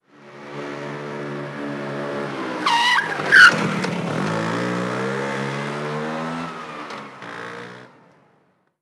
Moto marca Vespa frenazo 2
frenazo
motocicleta
Sonidos: Transportes